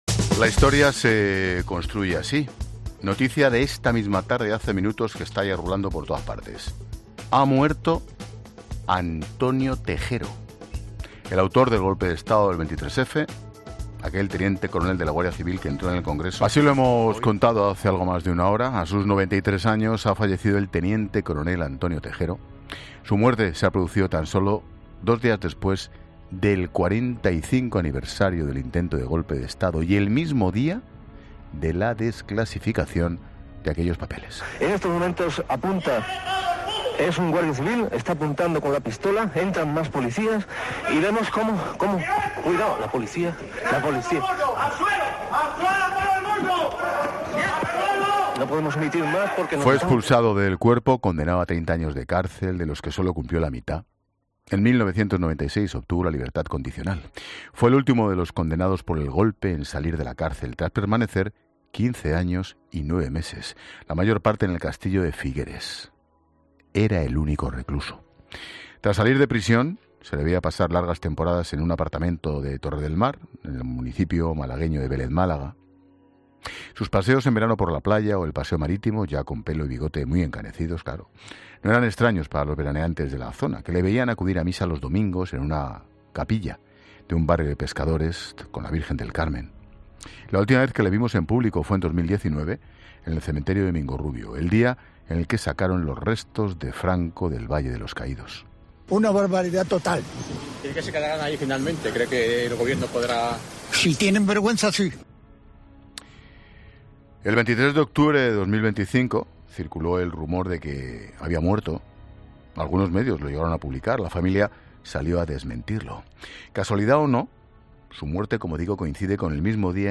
Expósito entrevista a Julia Navarro, testigo del 23-F en el Congreso